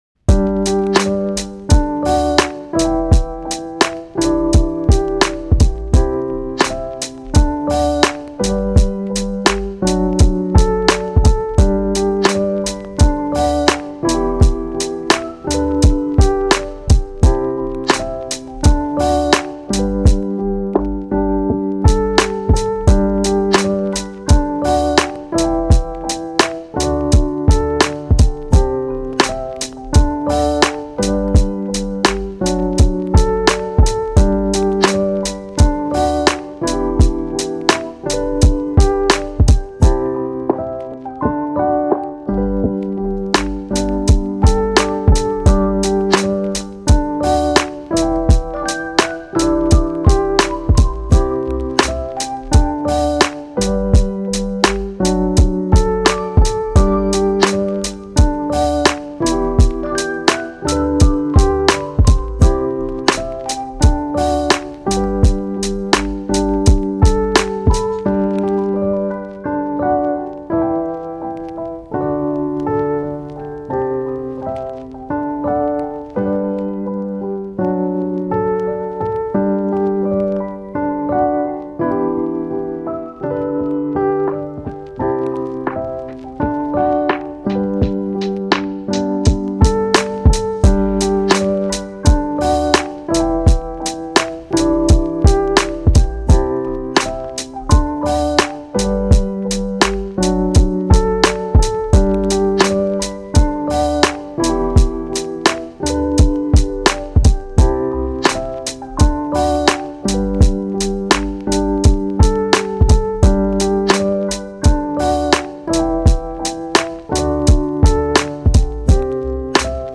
カフェミュージック チル・穏やか フリーBGM